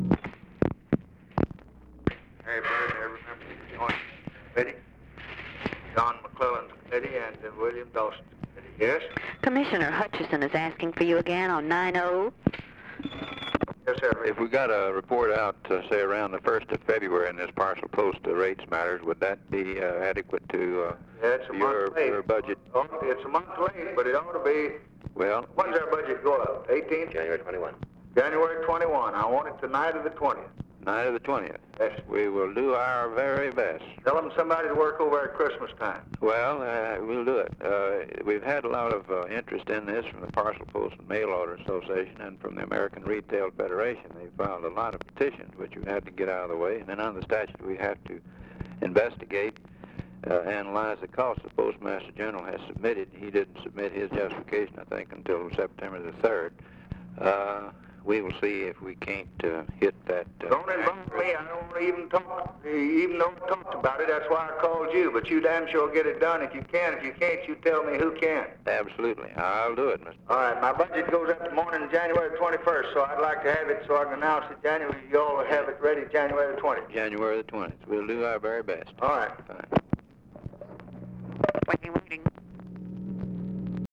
Conversation with EDWARD HUTCHISON, December 23, 1963
Secret White House Tapes